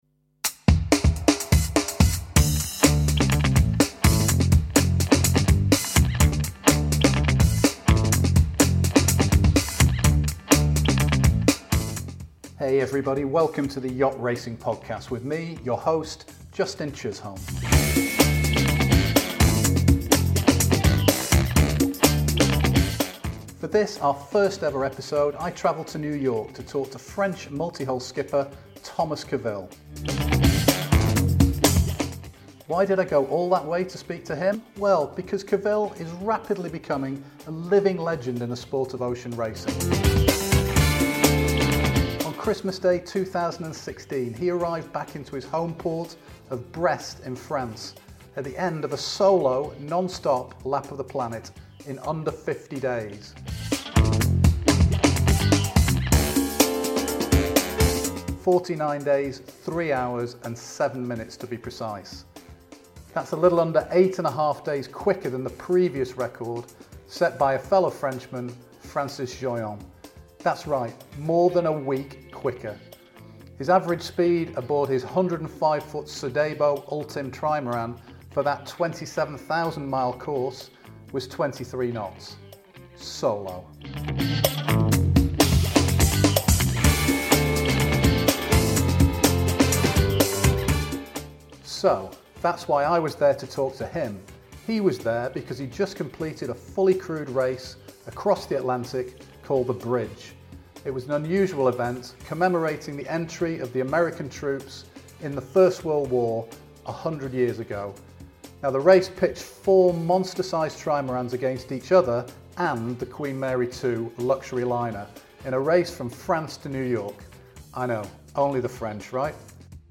For the very first episode of the Yacht Racing Podcast we travel to New York for the finish of The Bridge transatlantic race to speak exclusively to French record breaking solo round the world multihull skipper, Thomas Coville.